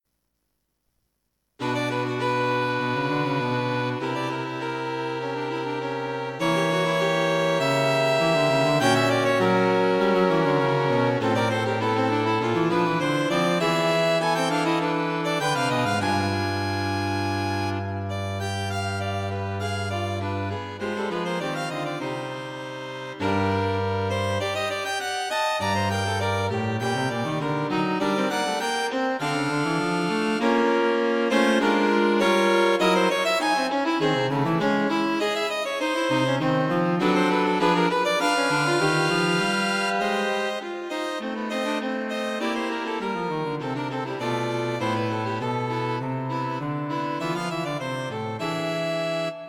String Quartet for Concert performance
A set of three String Quartets